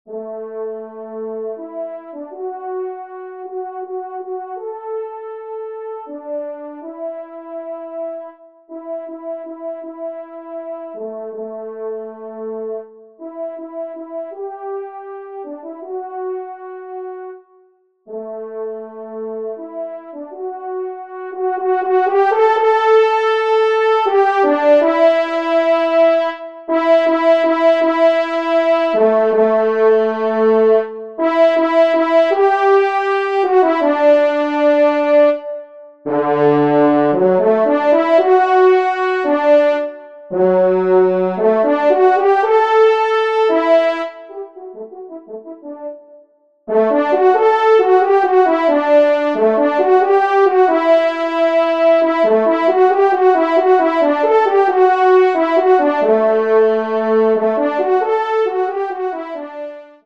Genre :  Divertissement pour quatre Trompes ou Cors en Ré
Pupitre 1°Trompe